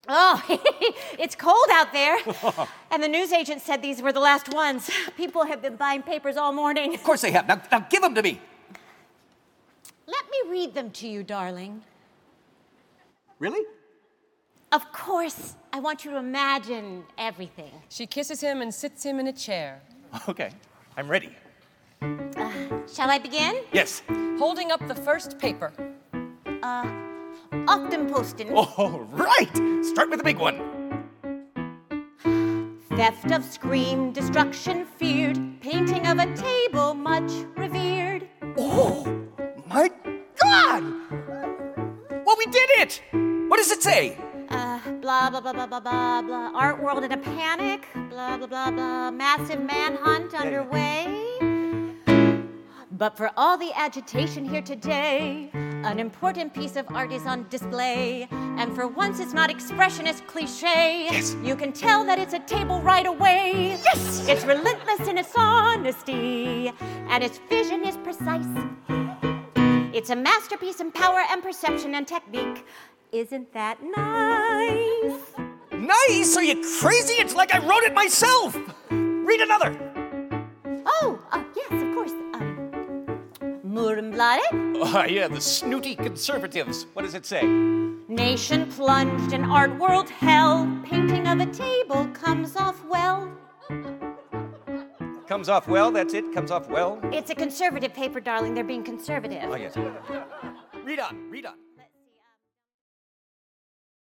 A musical. A comedy. A fantasy.
Producer / Keyboard